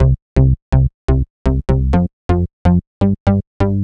cch_bass_chicago_125_Gm.wav